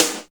46.03 SNR.wav